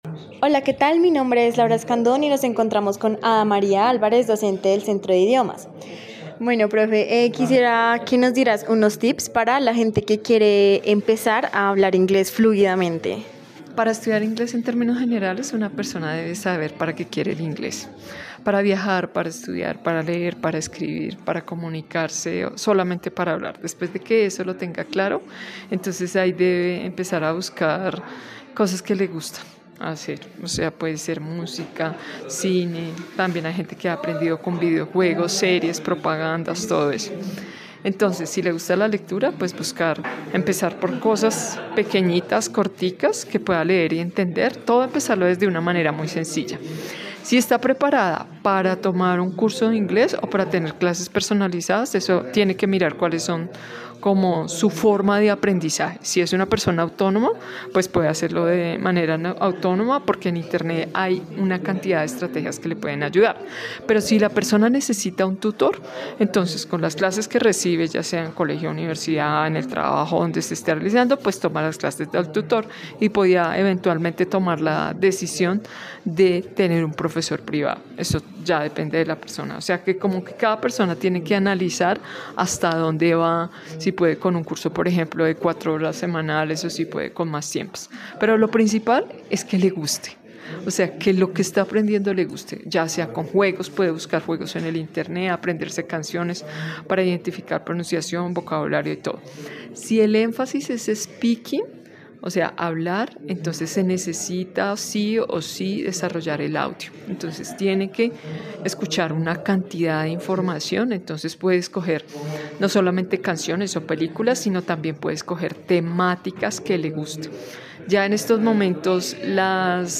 profesora de lingüística da unos tips para poner en práctica.